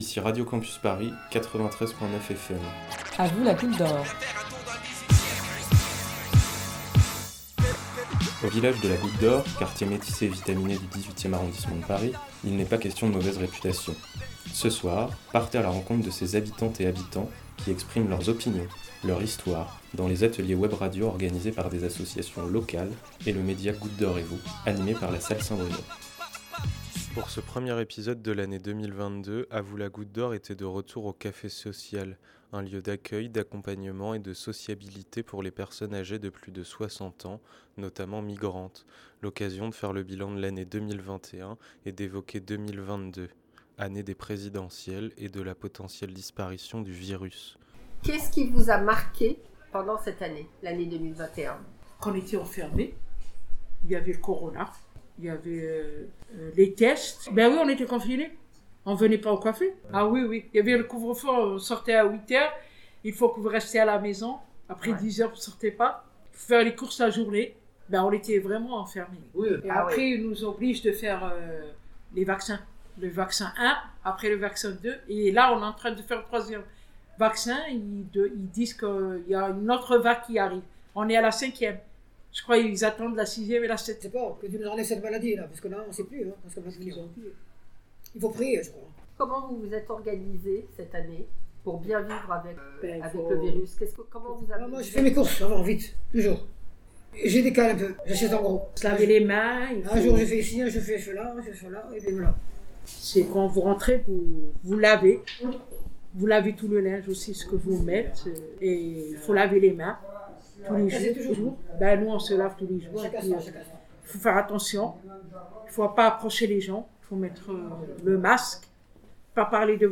Lors du dernier atelier webradio au Café Social, le premier de cette nouvelle année 2022, il a été question d’une fin d’année endeuillée par la covid, des perspectives incertaines pour les mois à venir.